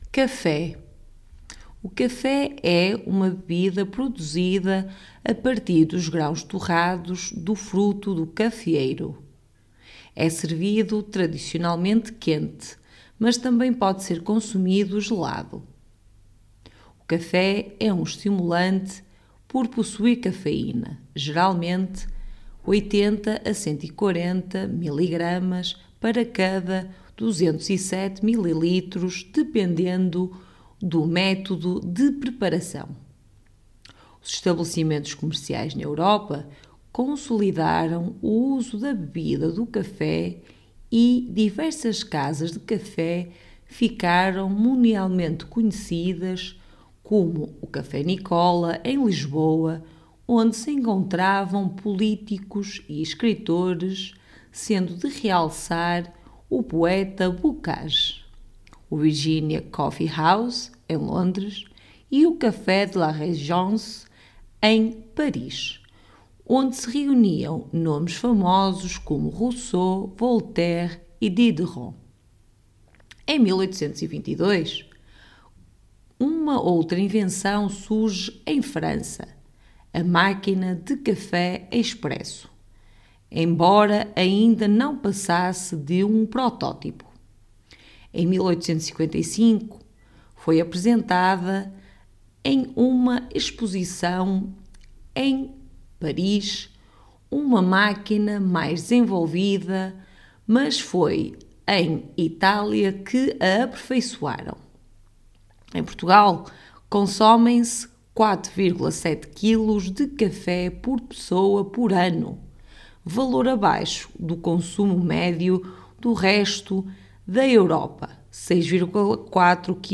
Our article text and audio are specifically aimed towards the Portuguese language from Portugal, and not from Brazil or other Portuguese speaking countries.